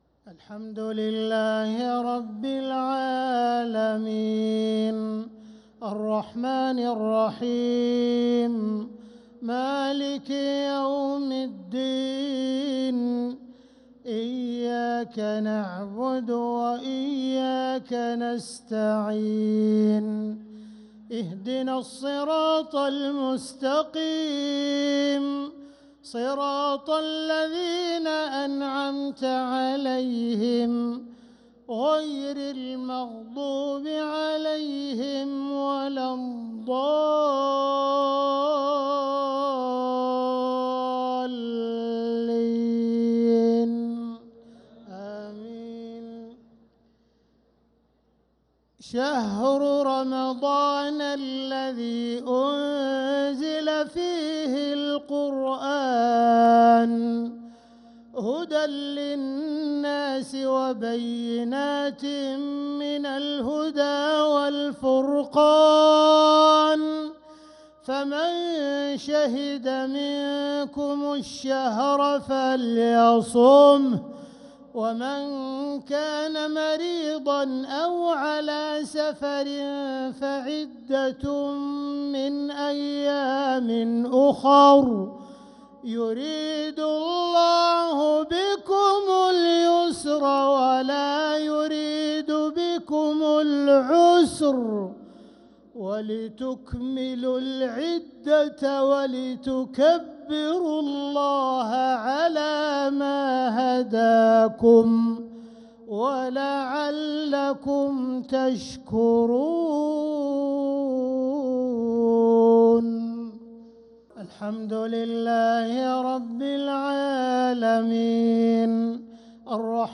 مغرب السبت 1-9-1446هـ من سورة البقرة 185-186 | Maghrib prayer from Surat al-Baqarah 1-3-2025 > 1446 🕋 > الفروض - تلاوات الحرمين